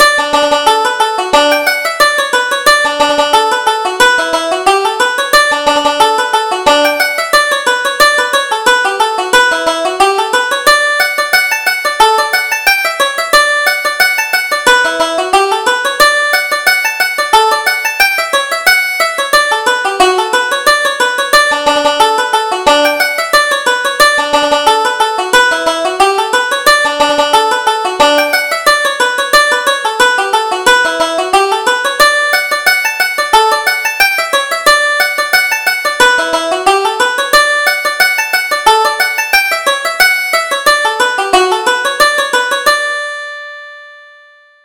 Reel: The Drogheda Lasses